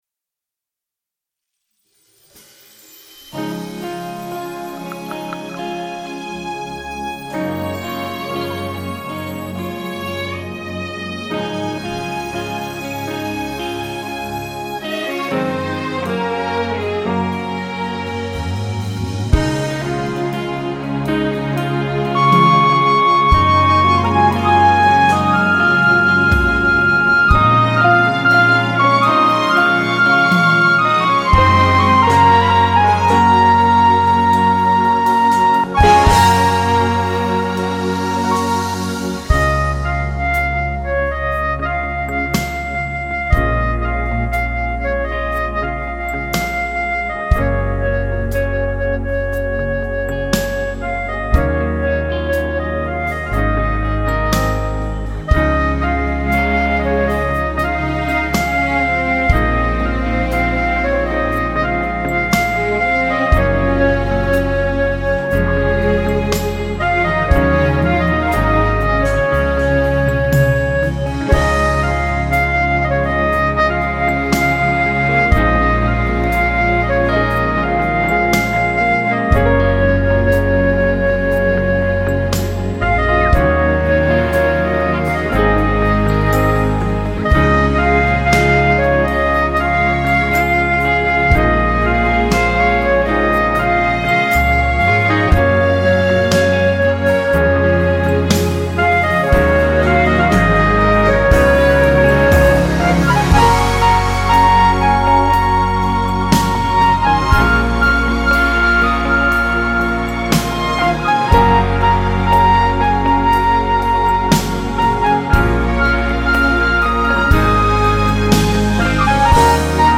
Powerful instrumental play